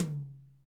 TOM RLTOM0PL.wav